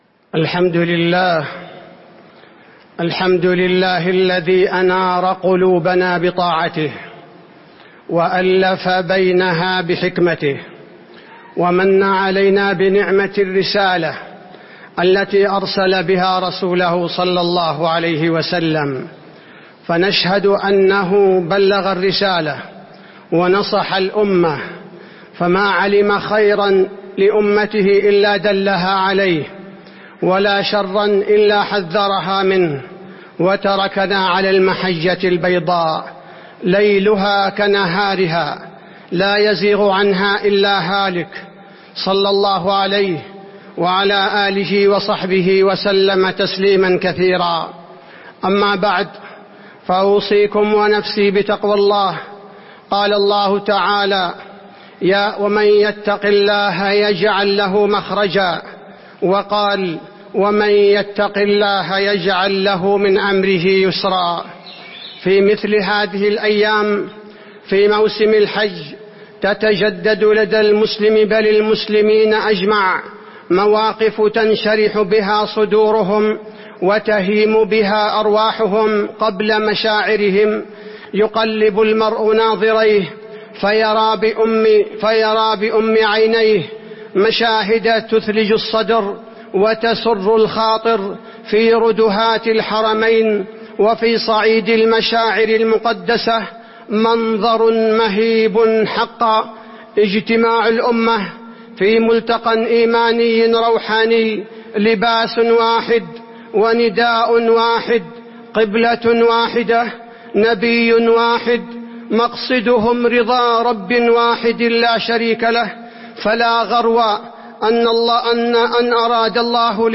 تاريخ النشر ٨ ذو الحجة ١٤٤٥ هـ المكان: المسجد النبوي الشيخ: فضيلة الشيخ عبدالباري الثبيتي فضيلة الشيخ عبدالباري الثبيتي الأمة الإسلامية في الحج